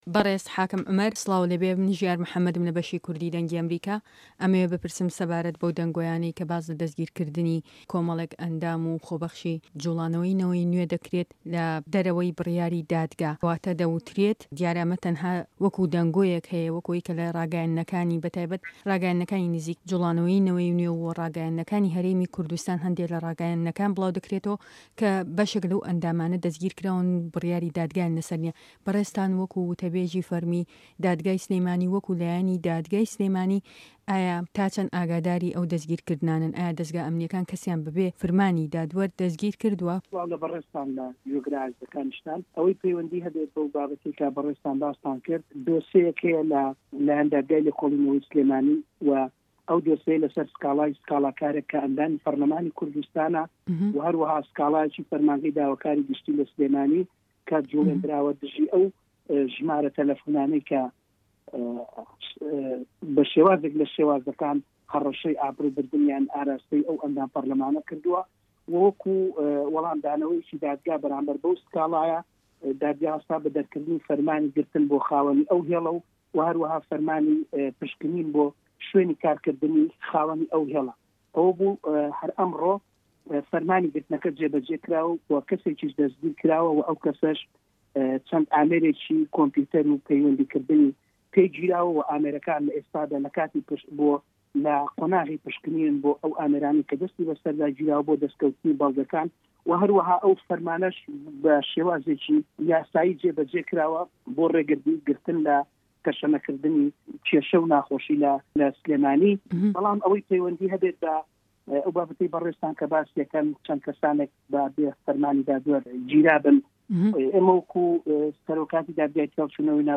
ده‌قی وتووێژه‌كه‌